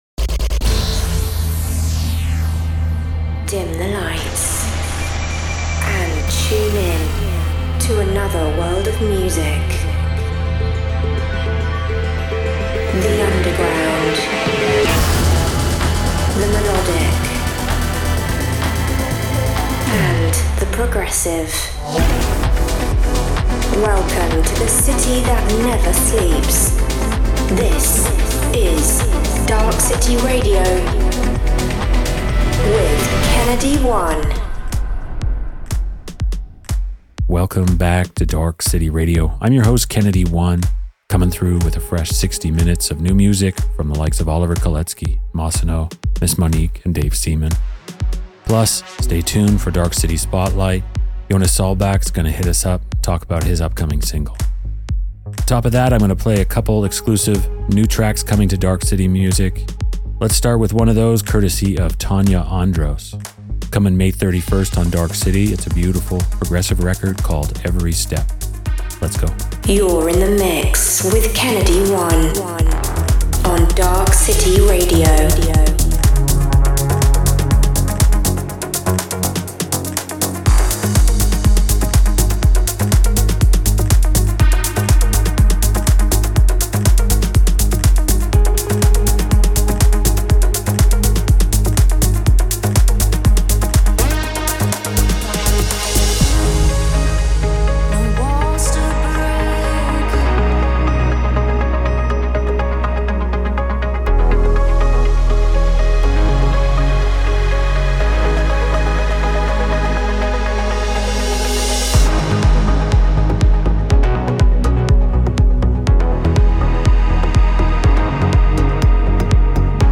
underground melodic techno